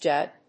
/dʒʌd(米国英語)/